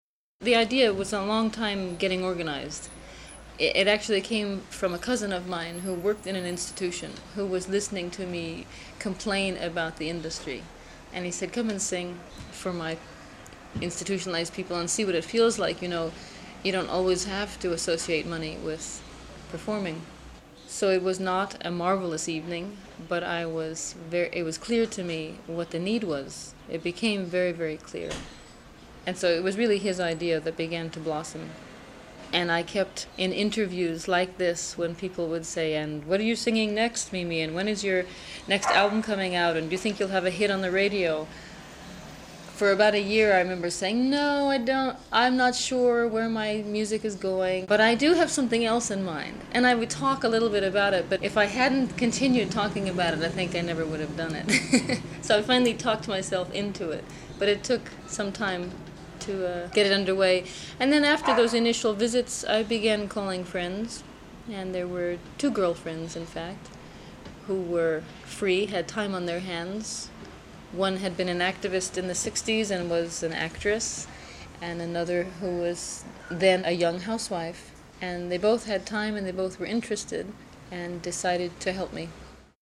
INTERVIEWS WITH MIMI:
for German public radio